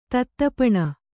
♪ tattapaṇa